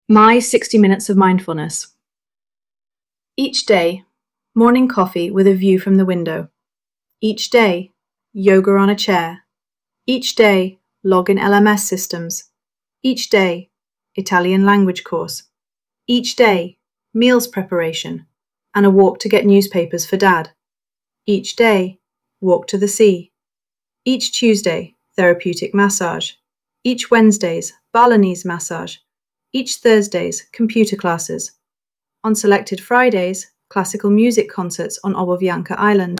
ElevenLabs_2025-08-25T10_11_34_Alice - British Female_pvc_sp100_s50_sb75_v3.mp3